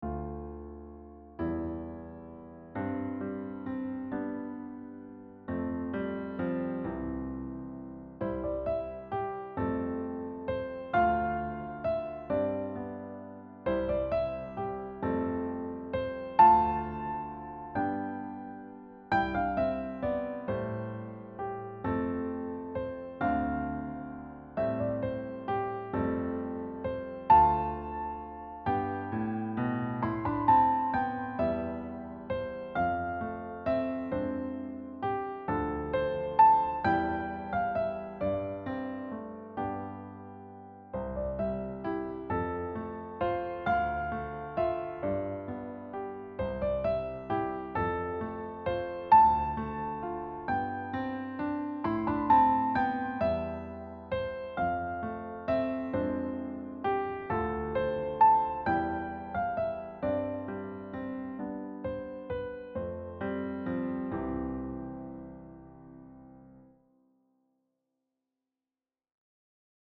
Key: Middle C position
Time Signature: 3/4 (lyrical waltz feel)
Level: Elementary